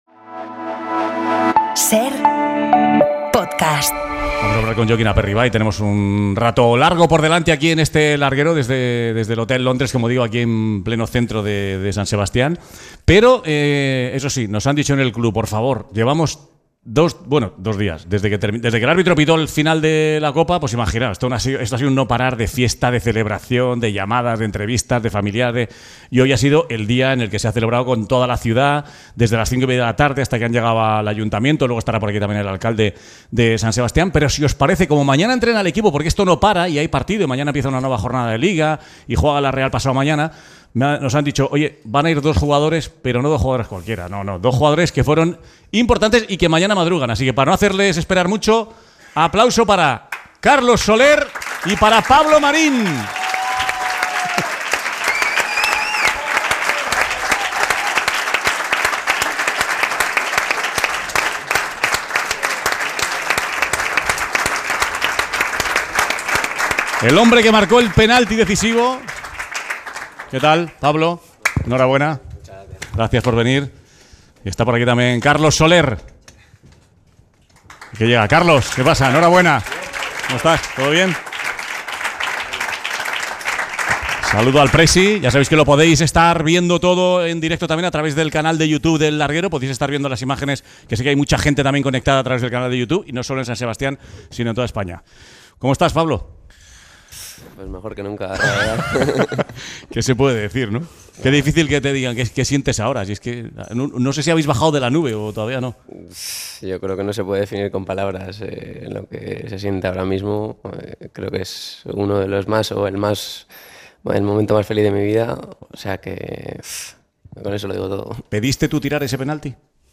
Los jugadores de la Real Sociedad hablan con Manu Carreño tras la rúa por Donosti